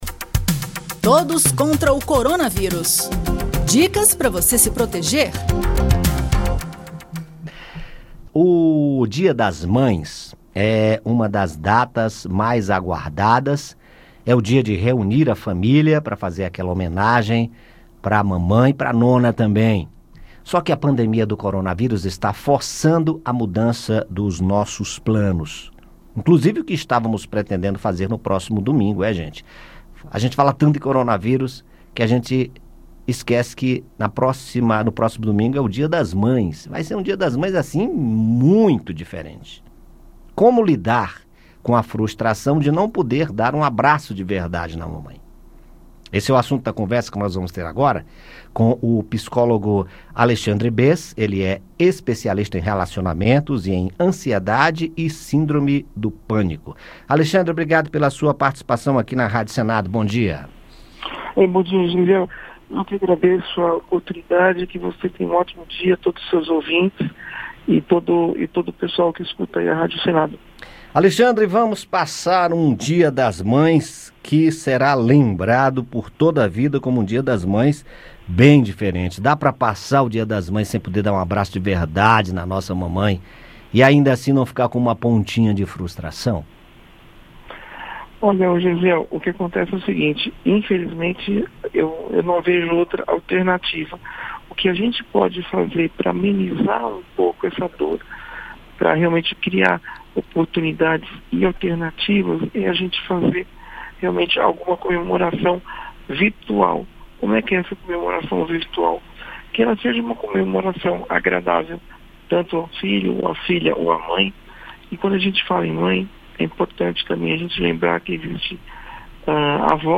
conversamos com o psicólogo